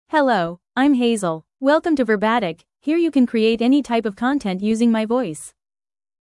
FemaleEnglish (United States)
Hazel is a female AI voice for English (United States).
Voice sample
Female
Hazel delivers clear pronunciation with authentic United States English intonation, making your content sound professionally produced.